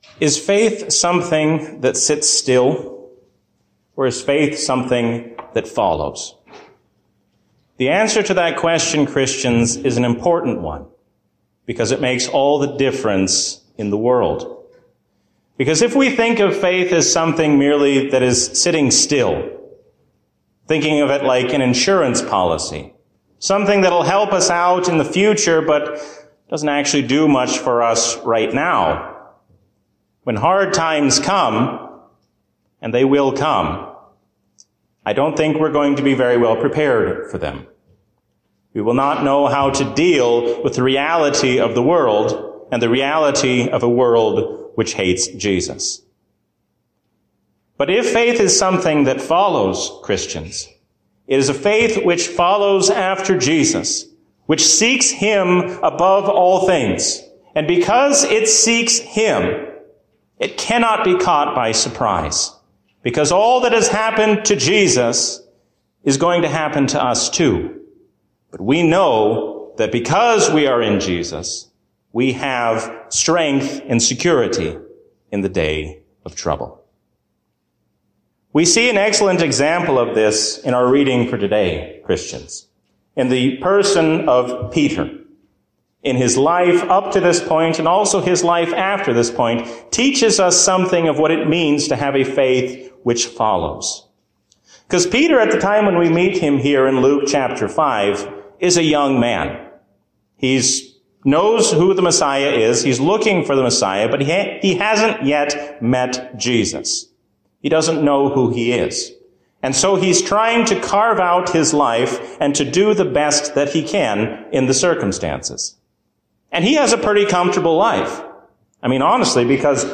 A sermon from the season "Trinity 2024." The ascension of Christ strengthens us to carry out the work of the Church while we wait for His coming.